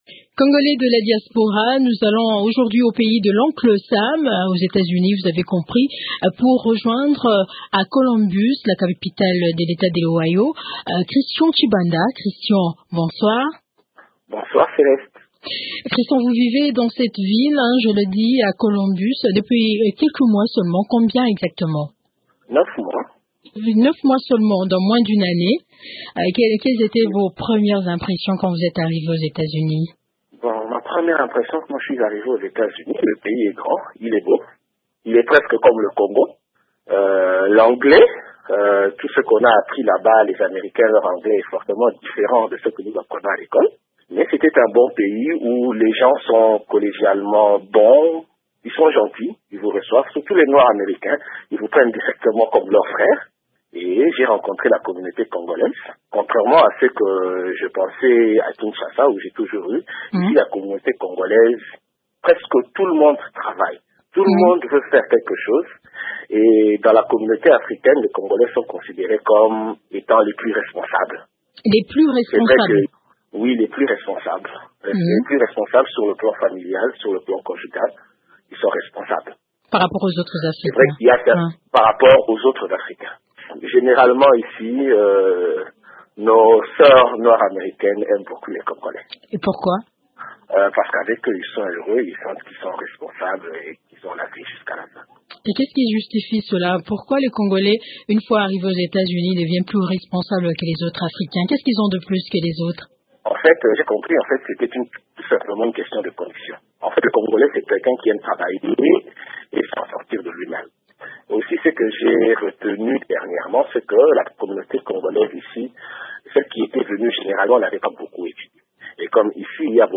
Dans cet entretien, il a témoigné: « Dans la communauté africaine, les Congolais sont considérés comme les plus responsables, sur le plan familial et conjugal.